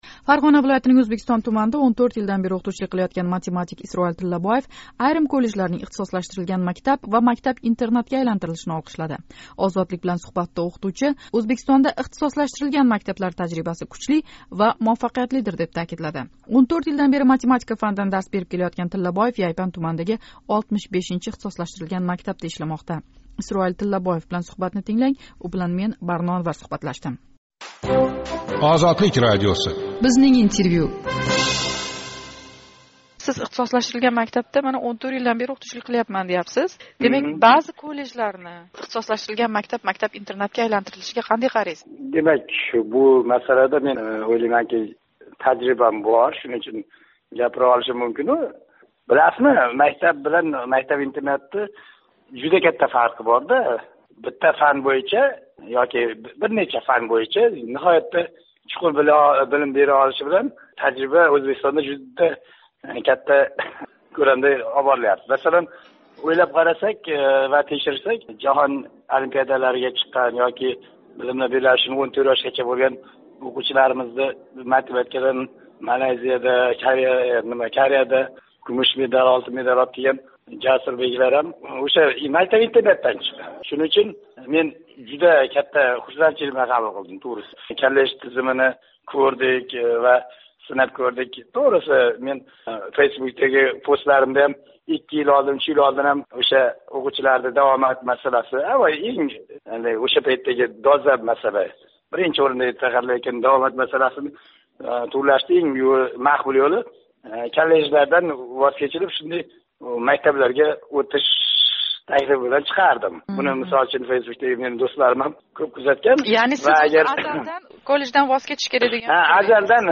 Ozodlik bilan suhbatda